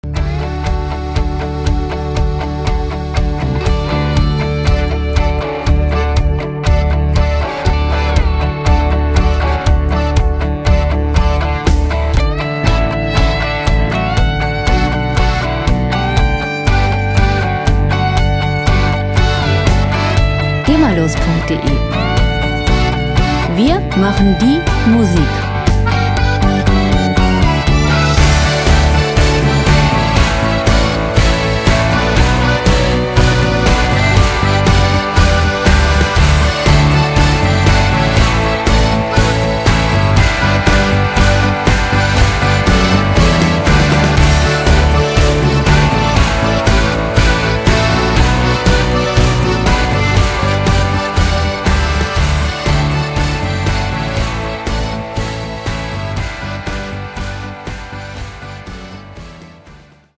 Schlager Pop Musik - Aprés Ski
Musikstil: Country-Rock
Tempo: 120 bpm
Tonart: D-Dur
Charakter: bodenständig, geerdet